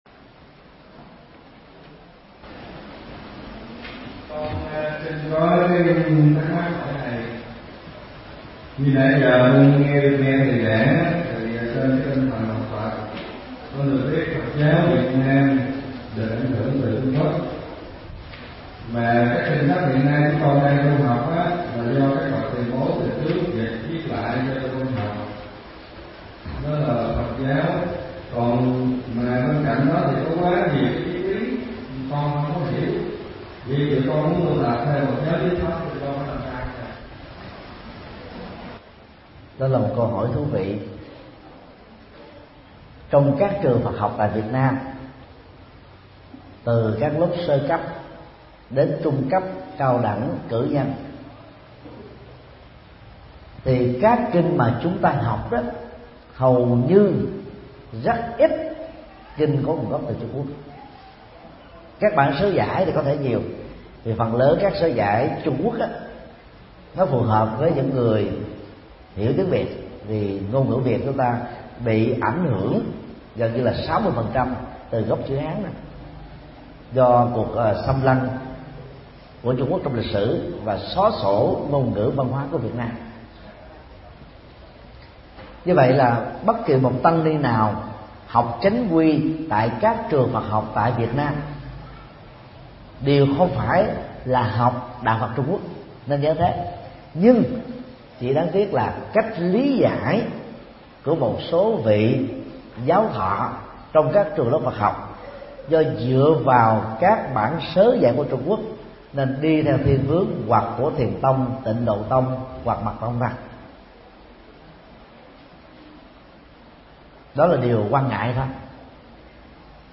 Nghe mp3 Vấn đáp: Phương pháp tu tập theo truyền thống đạo Phật nguyên thủy – Thượng Tọa Thích Nhật Từ